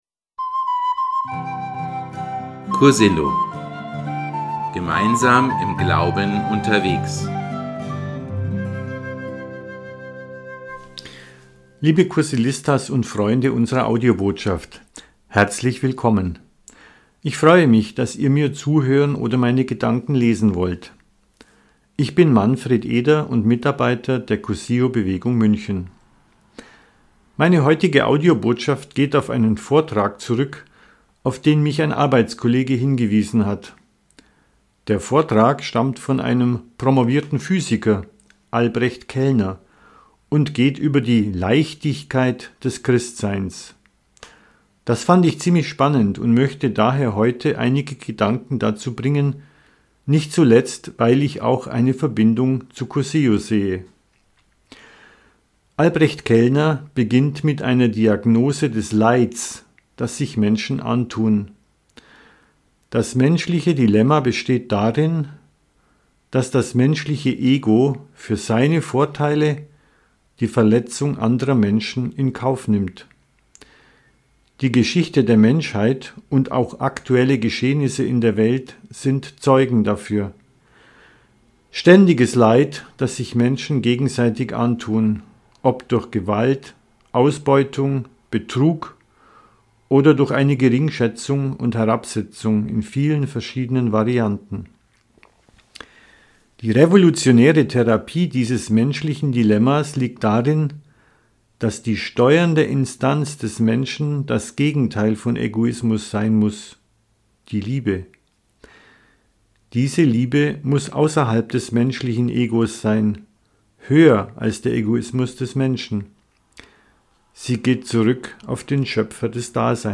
Audio-Botschaft vom 02.03.2025 Die Leichtigkeit des Christseins